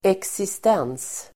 Uttal: [eksist'en:s]